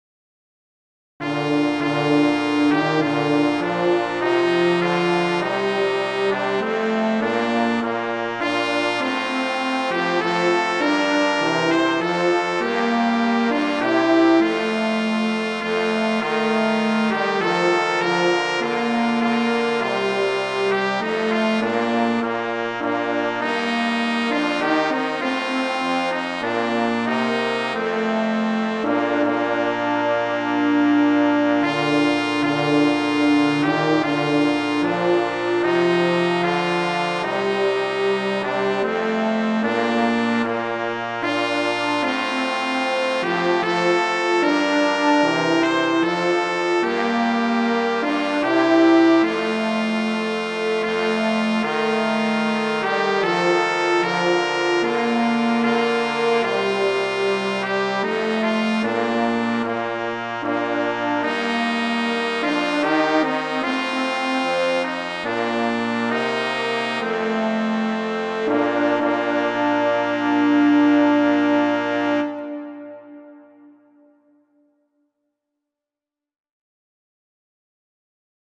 horn in F:
trombone: